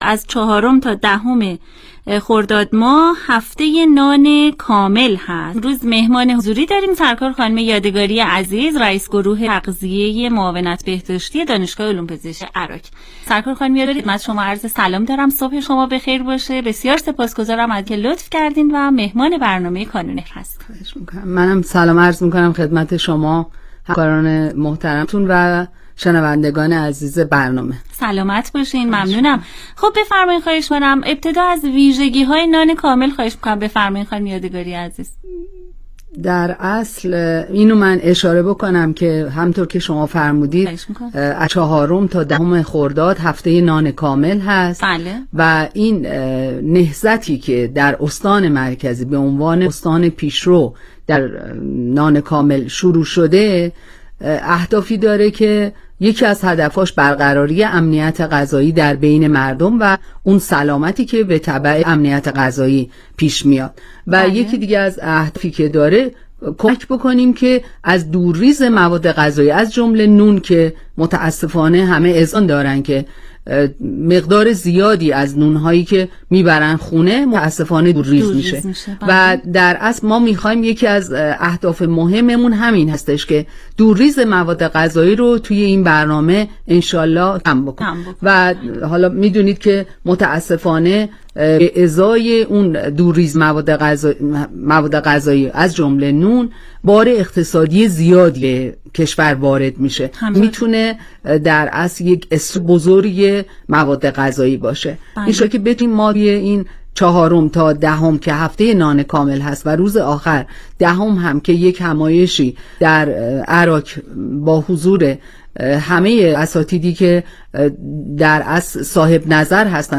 برنامه رادیویی کانون مهر &nbsp